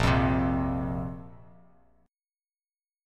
Bbsus2 chord